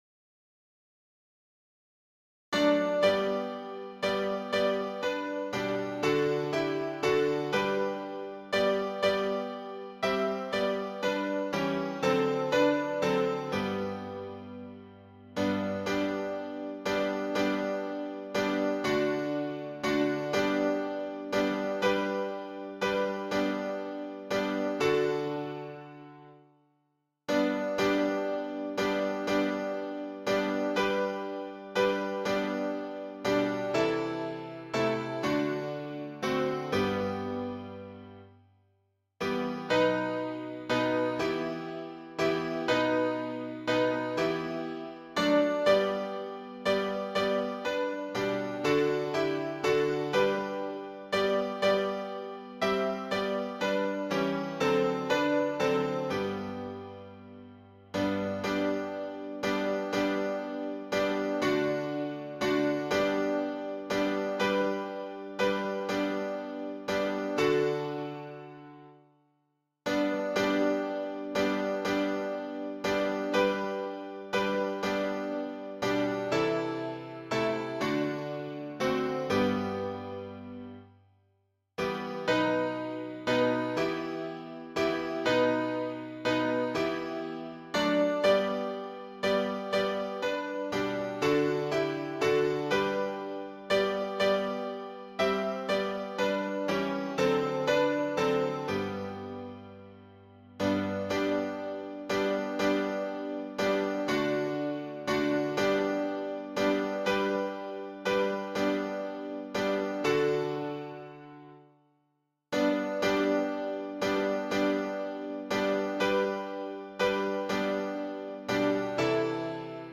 伴奏
示唱
这是一首基督教界最受欢迎的圣诗之一。